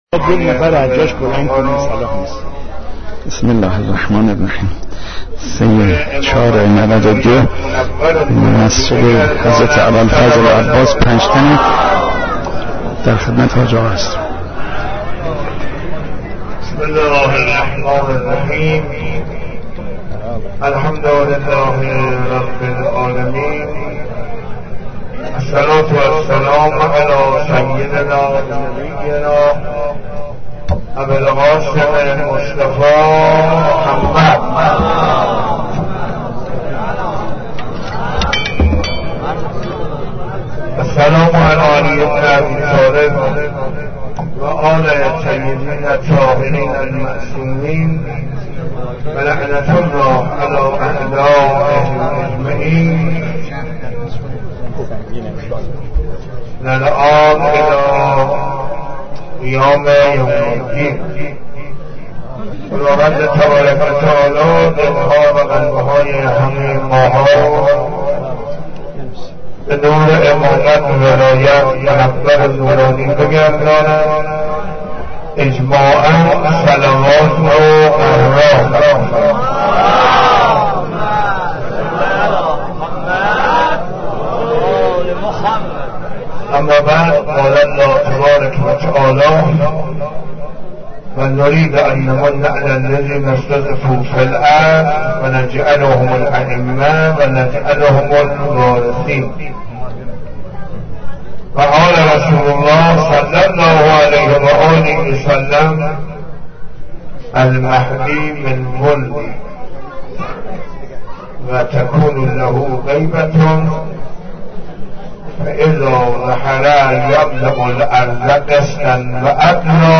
1392-4-3_jo_masjed_abalfazl_panjtan.mp3